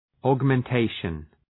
Προφορά
{,ɔ:gmen’teıʃən}